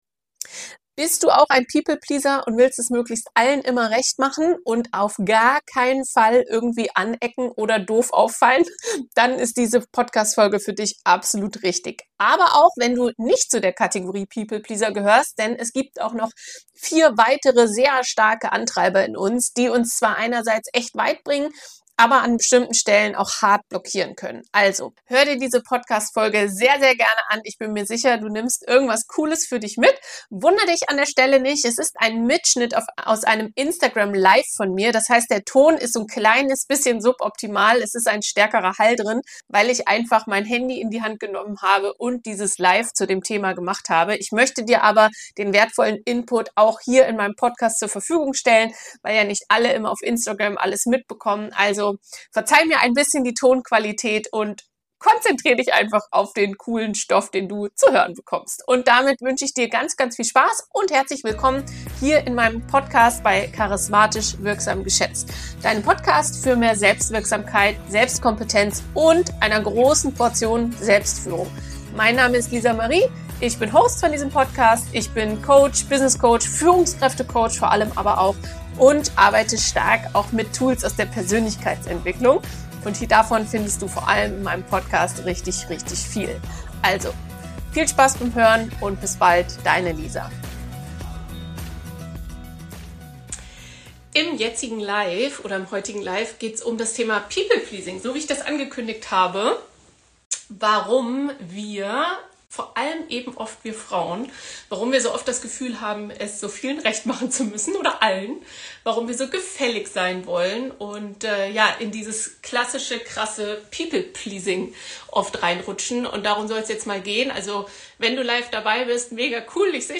Diese Folge ist ein Mitschnitt aus einem Instagram Live von mir. Lerne, warum du ins People Pleasing rutscht, wie du das ändern und entschärfen kannst und warum das so wertvoll ist.